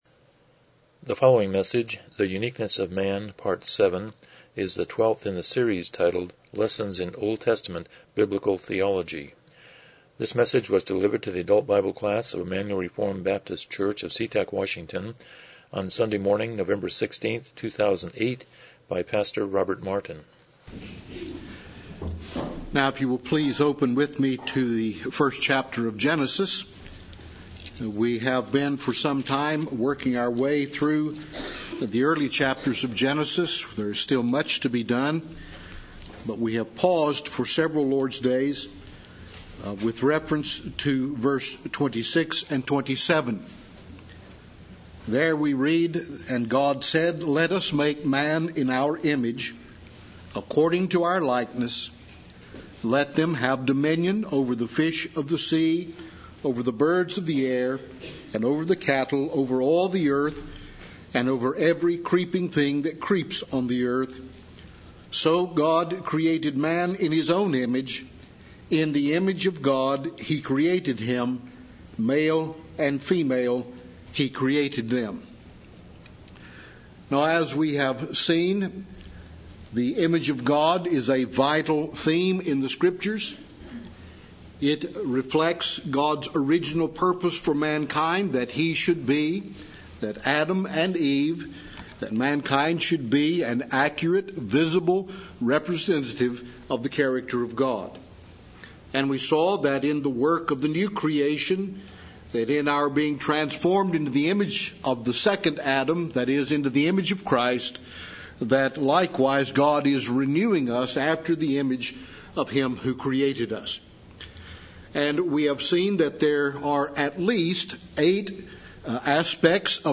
Lessons in OT Biblical Theology Service Type: Sunday School « 47 Chapter 6.2-6.4